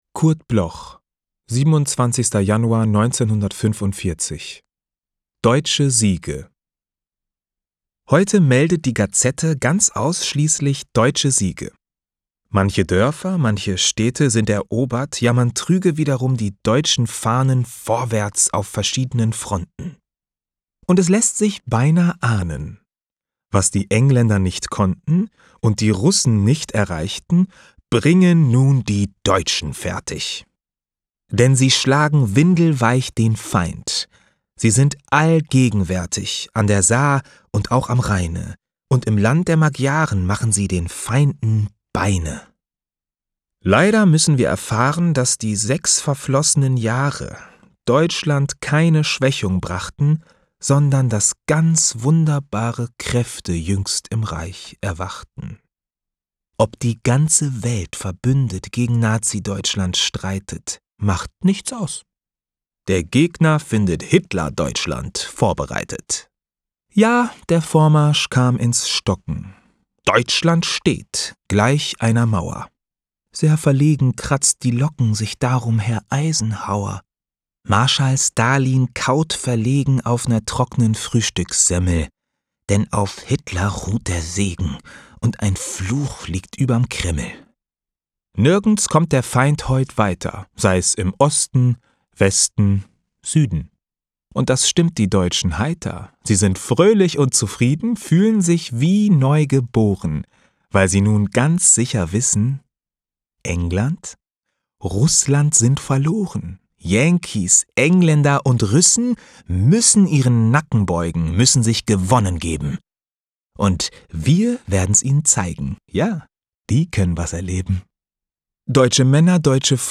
Recording: studio_wort, Berlin · Editing: Kristen & Schmidt, Wiesbaden